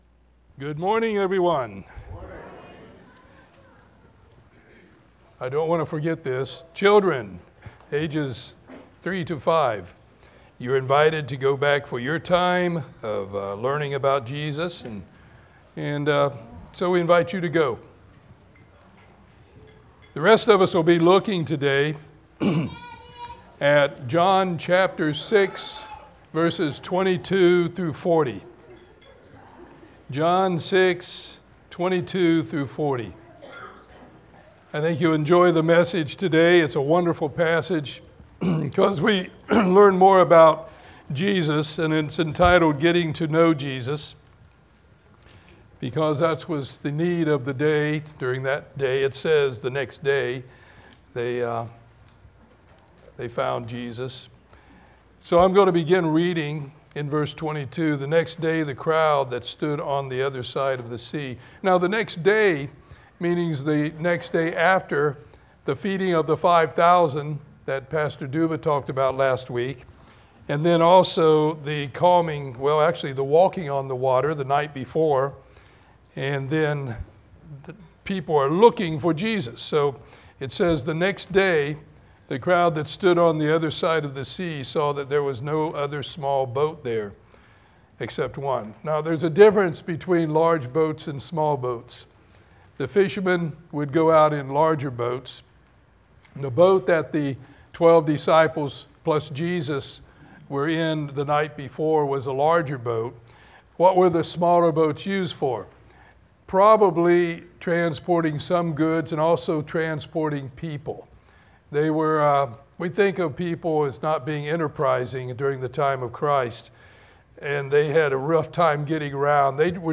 This passage from God's Word reveals the confusion of the people as to who Jesus really was. It also reveals the answers to that confusion. Sermon